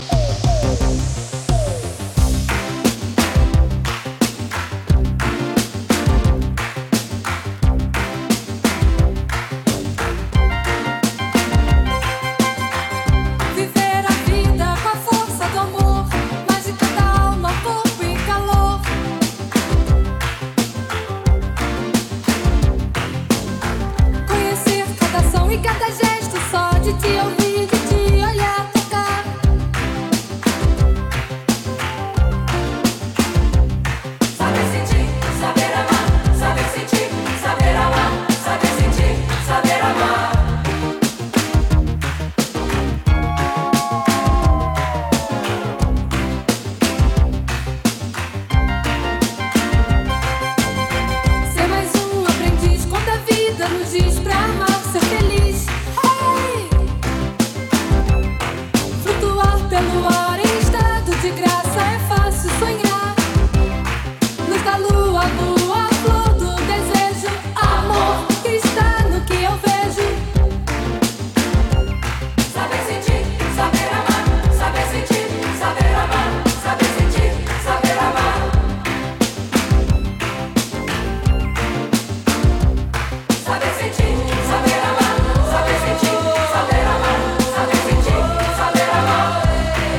ジャンル(スタイル) NU DISCO / RE-EDIT